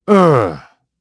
Siegfried-Vox-Deny.wav